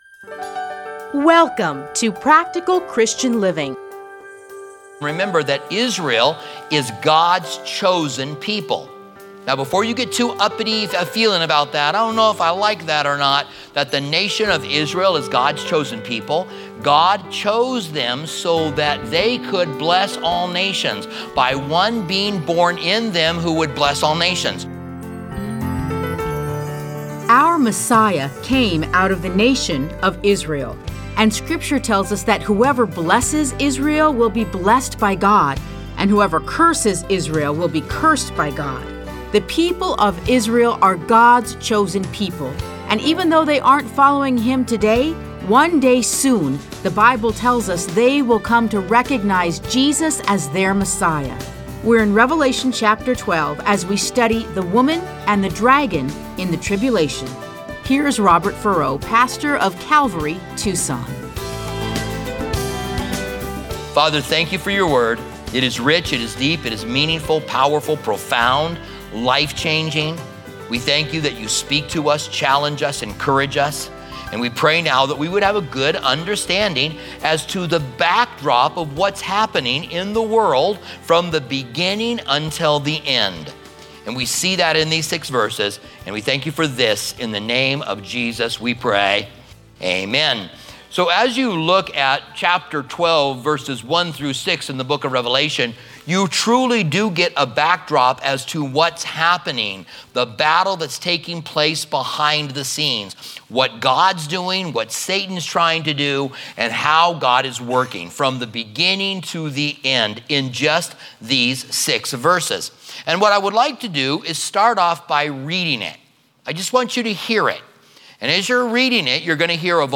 Listen to a teaching from Revelation 12:1-6.